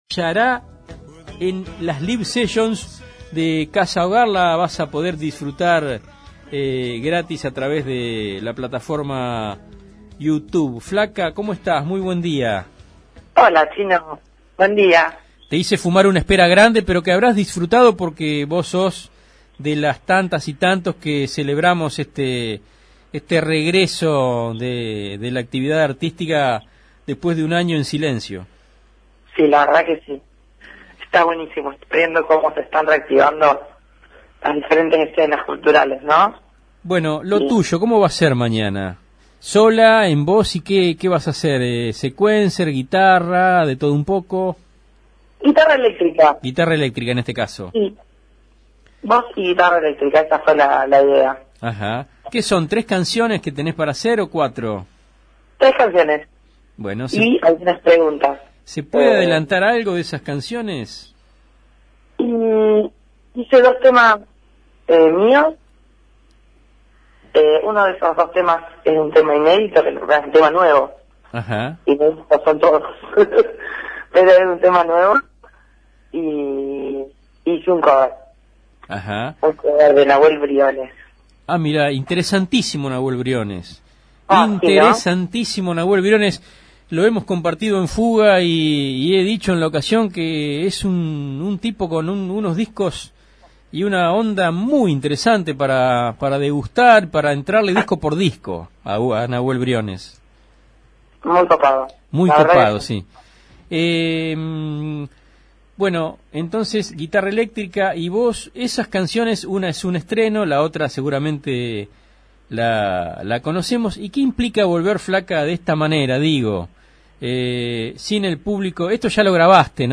cantante